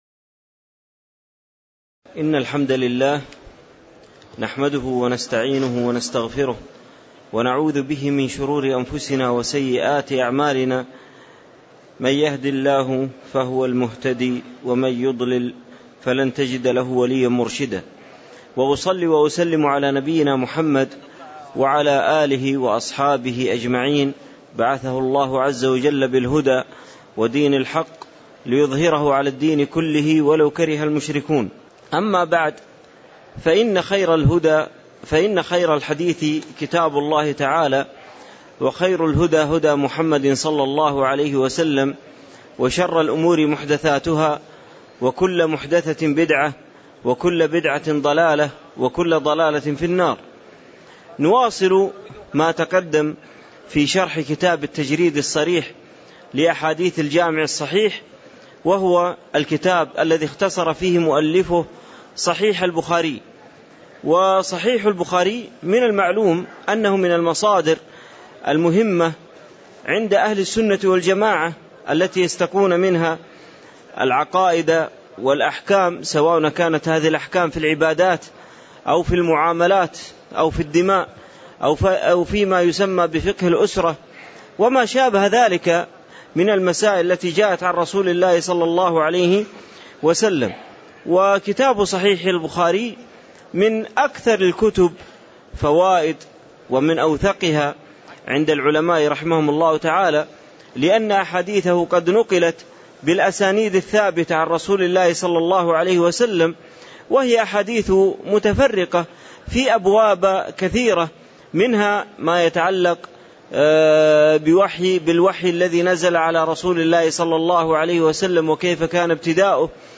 تاريخ النشر ٢٦ ذو الحجة ١٤٣٧ هـ المكان: المسجد النبوي الشيخ